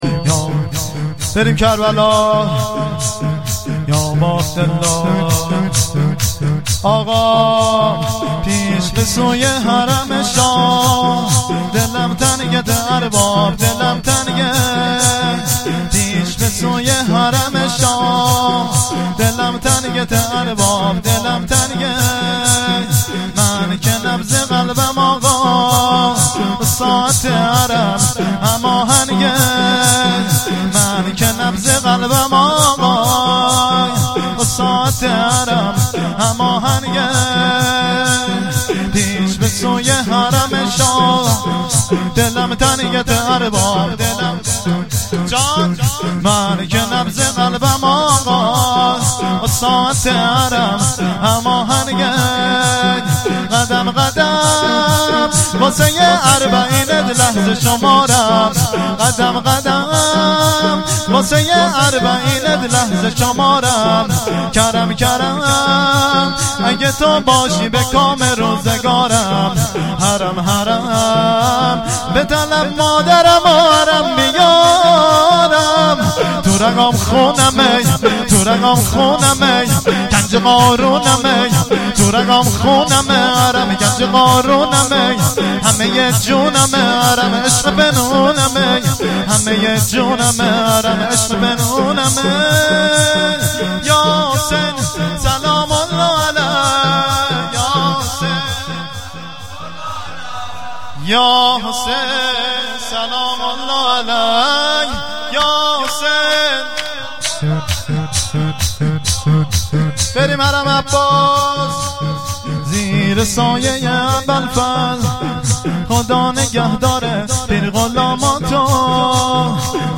شور مراسم بدرقه زائران اربعین